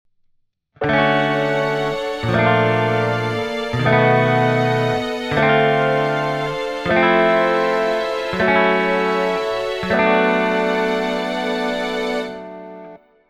As you can see and hear, each of these chords contains the note A, keeping the entire movement cohesive. Despite that, the progression itself is “chaotic” in the sense that it has no clear tonal center or home base.
17.5-an-A-in-Every-Chord.mp3